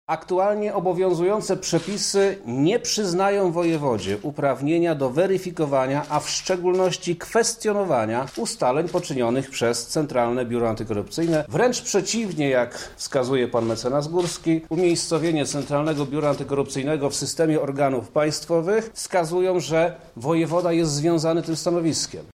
– Rola wojewody w konflikcie prezydenta z prawem jest trzeciorzędna – mówi Przemysław Czarnek.